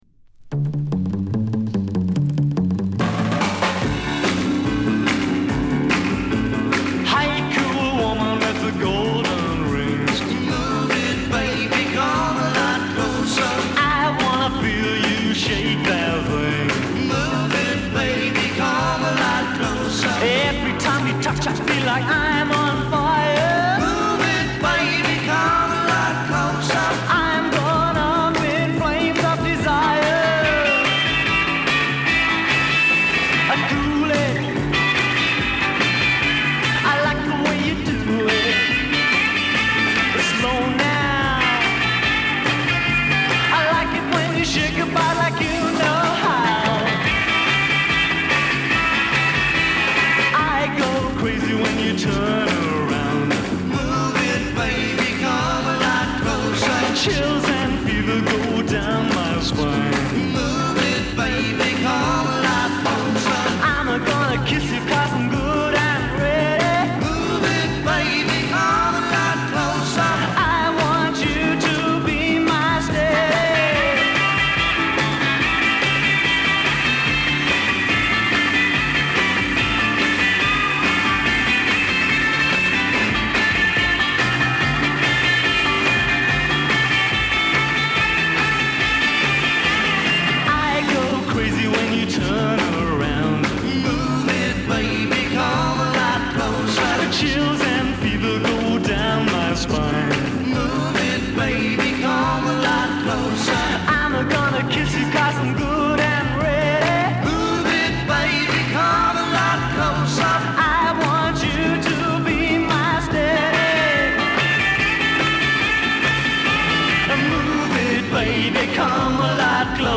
英国で60年代初頭〜中頃に活躍し人気を博したシンガー。